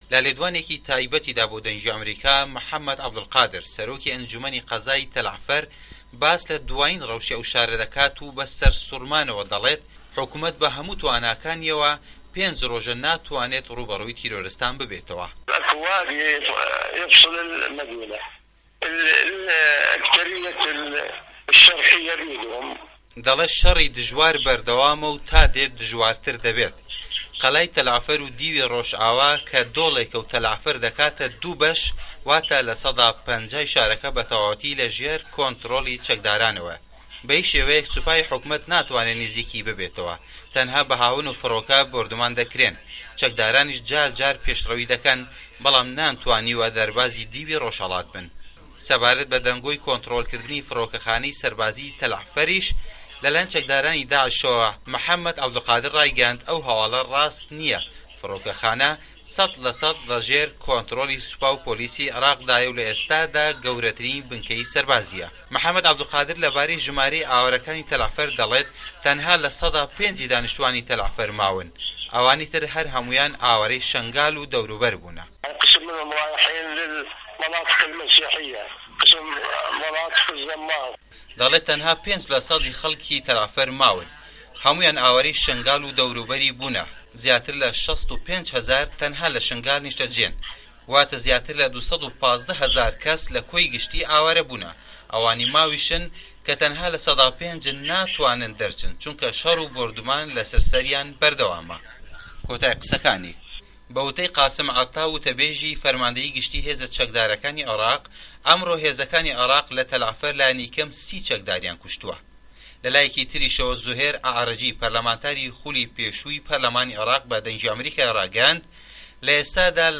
له‌ لێدوانه‌که‌یدا بۆ به‌شی کوردی ده‌نگی ئه‌مه‌ریکا محه‌مه‌د عبولقادر نکوڵی له‌و ڕاپۆرته‌ هه‌واڵیانه‌ش ده‌کات که‌ ده‌ڵێن داعش ده‌ستی گرتووه‌ به‌سه‌ر فڕۆکه‌خانه‌ی ته‌له‌عفه‌ردا.